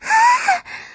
moan9.ogg